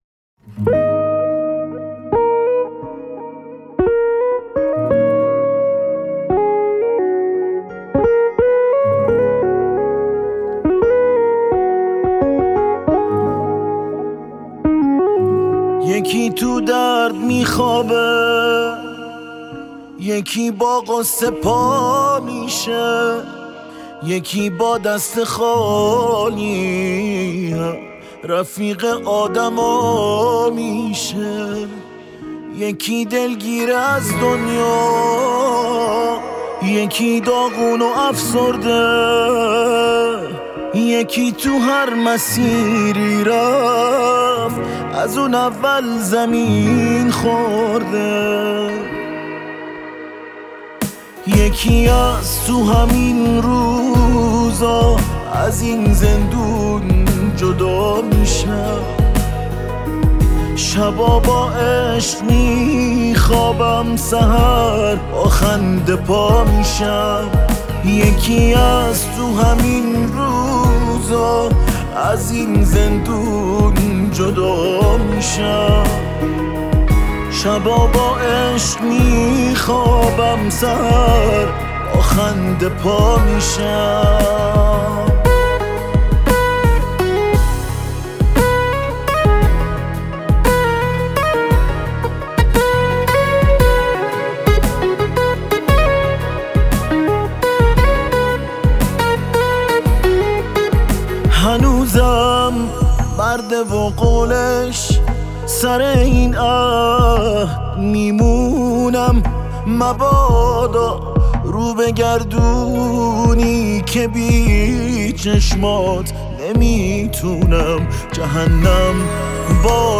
خواننده پاپ